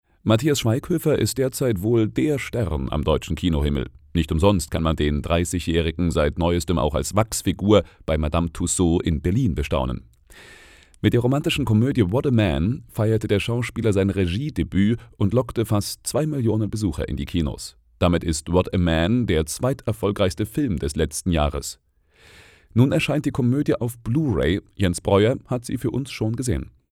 Sprecher mit tiefer, sonorer, warmer Stimme.
Sprechprobe: eLearning (Muttersprache):